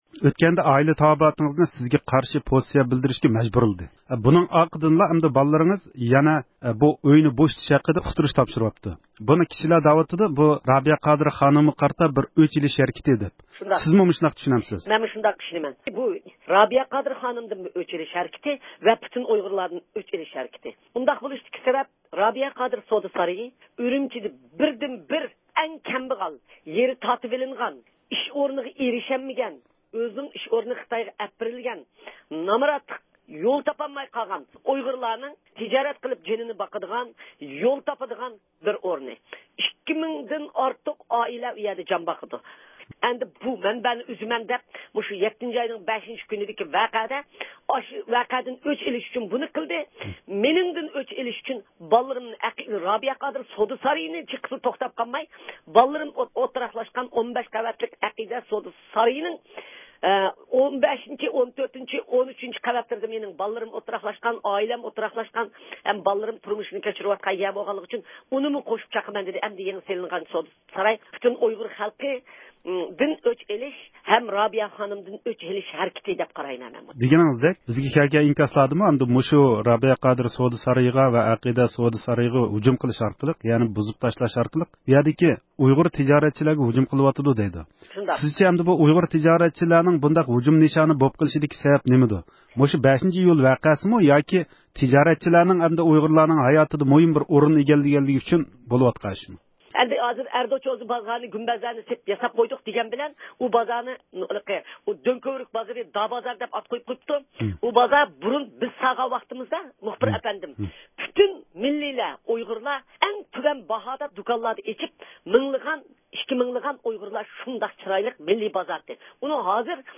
بۇ ئەھۋالغا قارىتا، رابىيە قادىر خانىم رادئىومىز مۇخبىرىنىڭ زىيارىتىنى قوبۇل قىلىپ، خىتاينىڭ بۇ قىلمىشلىرىغا ئىپادىسىنى بىلدۈردى.